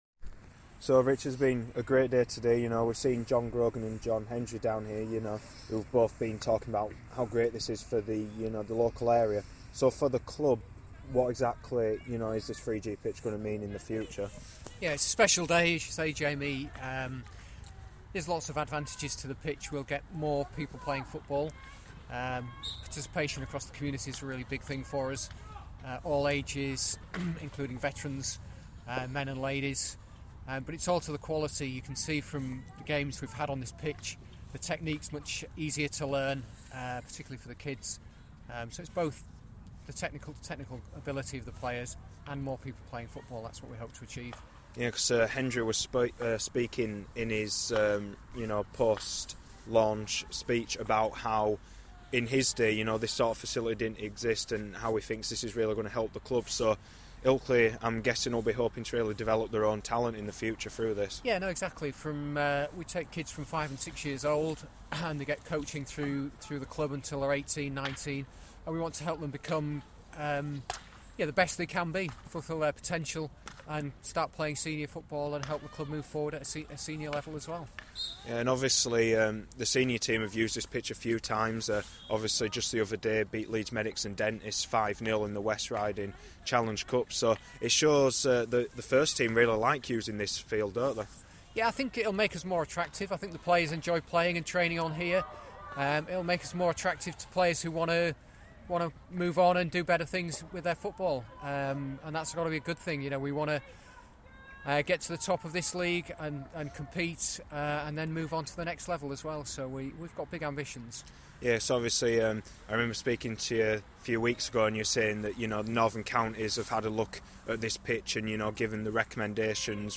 speaking after the 3G pitch launch.